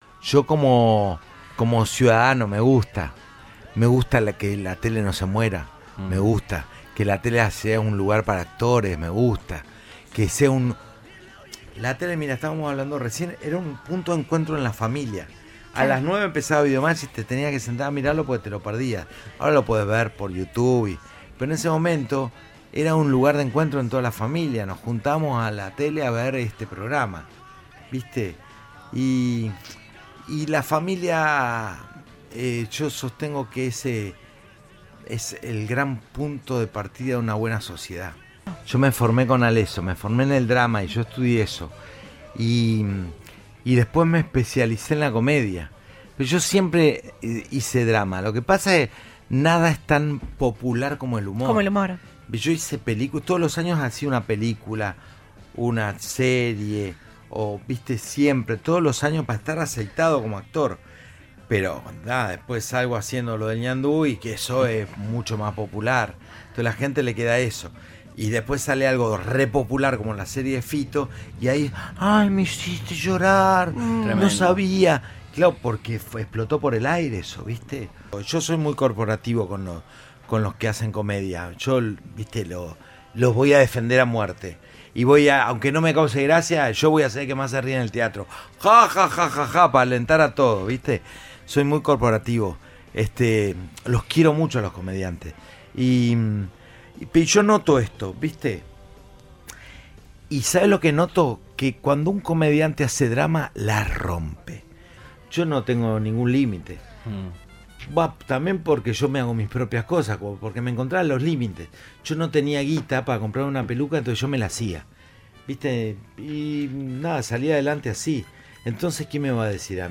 Audio. "Campi", de visita en Cadena 3 Rosario antes de su show en el Broadway.
Entrevista